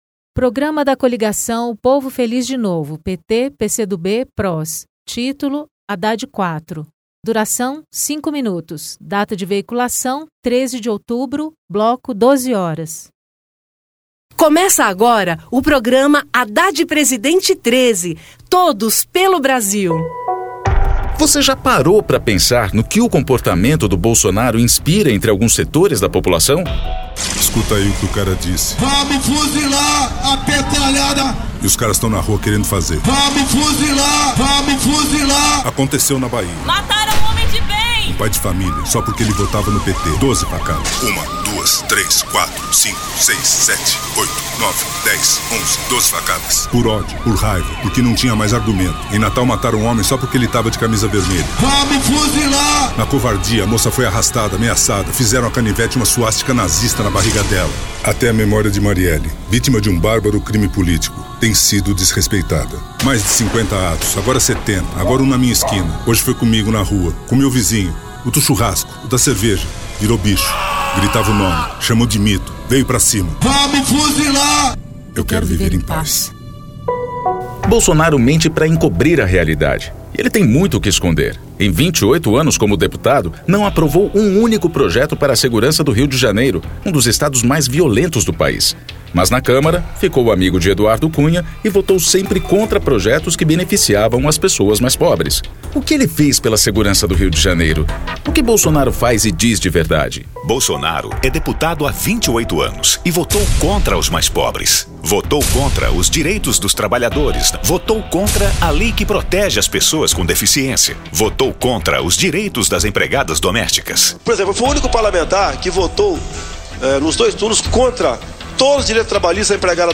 Descrição Programa de rádio da campanha de 2018 (edição 34), 2º Turno, 13/10/2018, bloco das 12:00hs